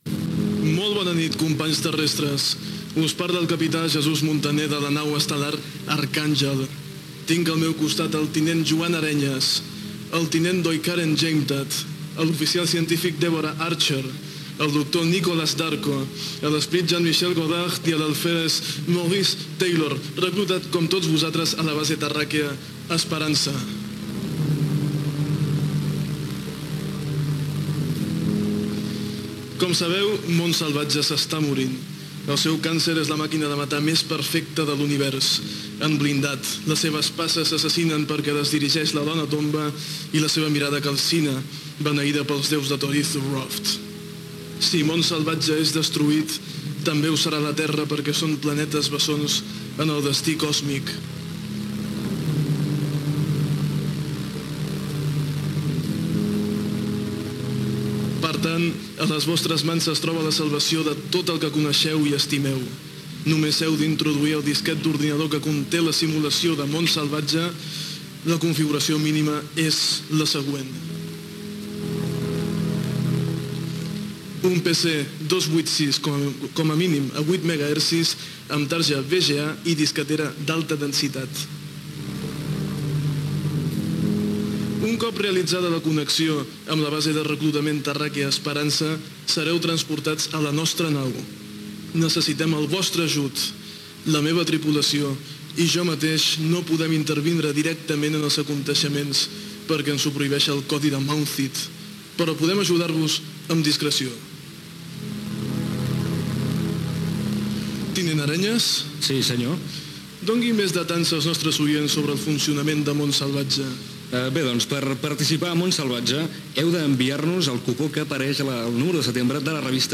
Presentació de l'equip, equip informàtic necessari per jugar i com aconseguir el videojoc, premis als guanyadors, contacte amb la base Esperança, un oïdor de Barcelona participa al programa